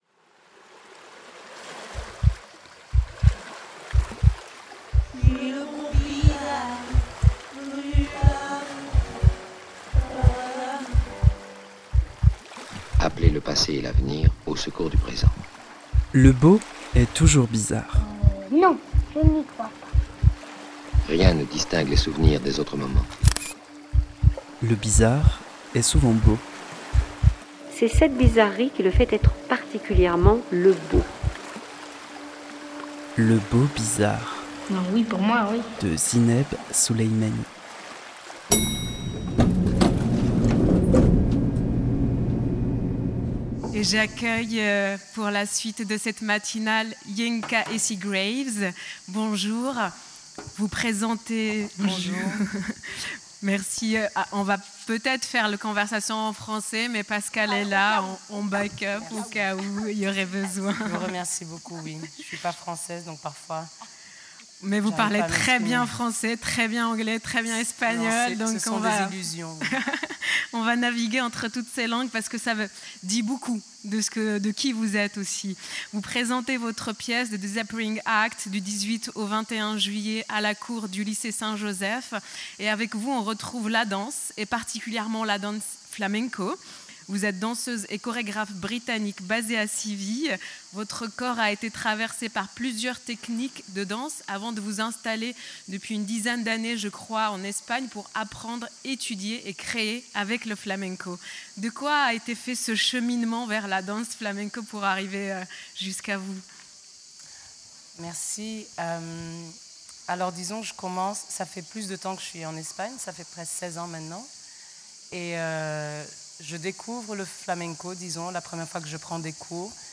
dans le cadre de la matinale du Festival d’Avignon.